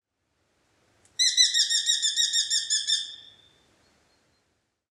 Canto